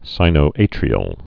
(sīnō-ātrē-əl)